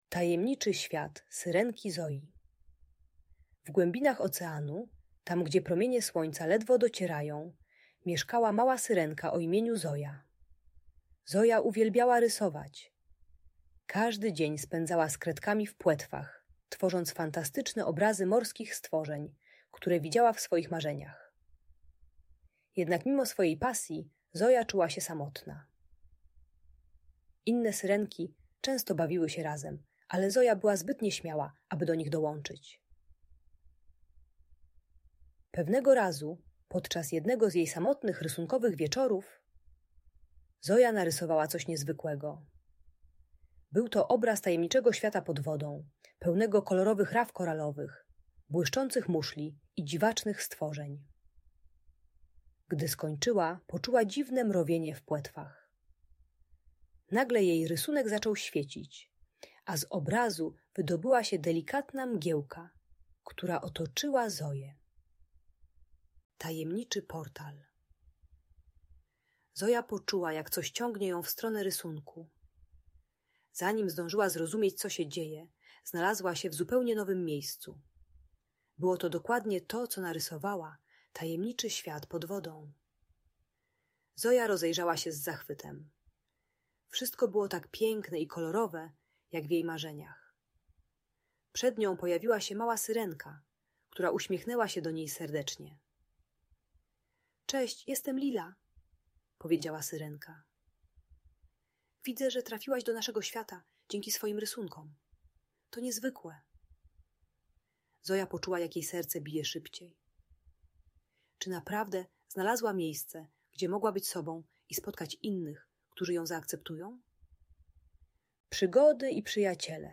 Tajemniczy Świat Syrenki Zoji - Audiobajka